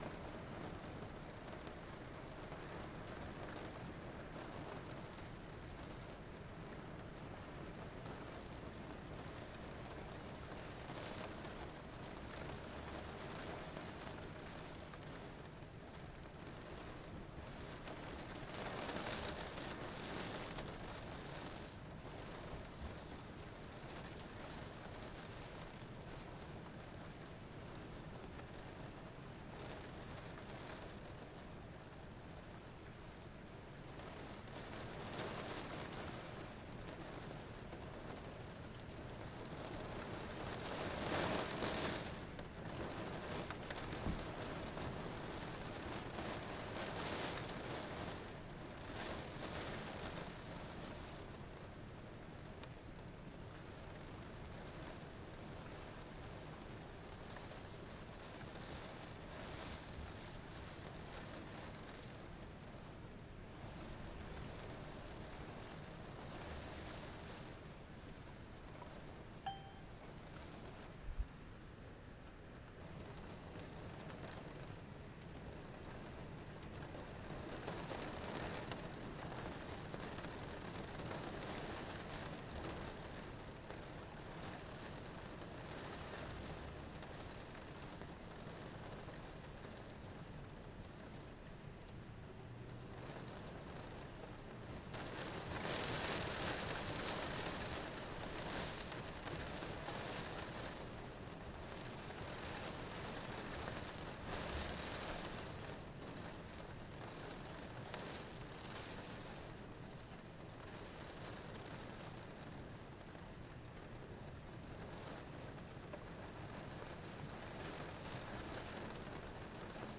Sound of rain against my window
92201-sound-of-rain-against-my-window.mp3